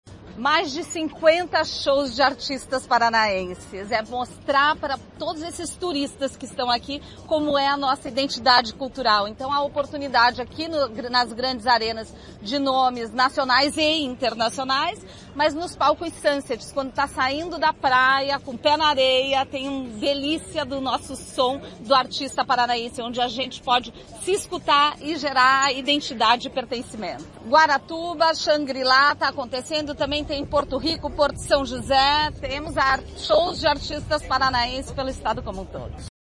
Sonora da secretária da Cultura, Luciana Casagrande Pereira, sobre o primeiro fim de semana de shows do Verão Maior Paraná